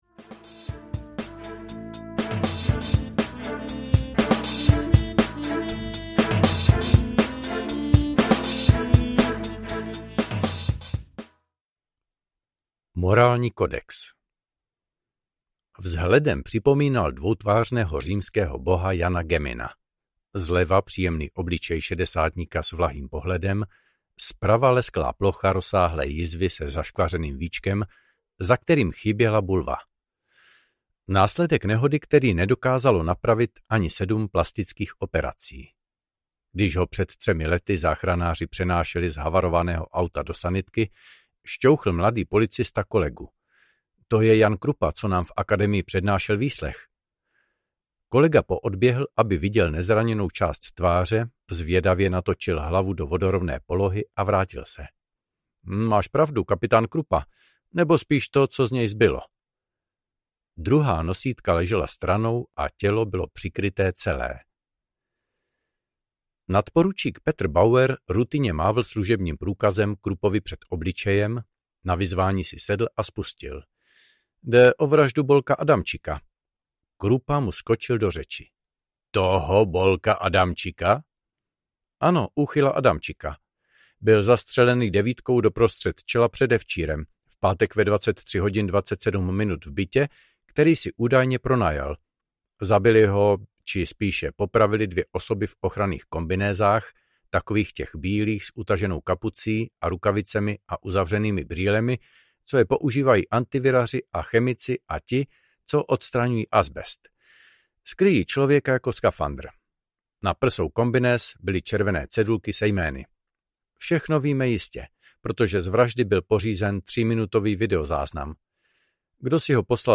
Vymazlené vraždy 1 audiokniha
Ukázka z knihy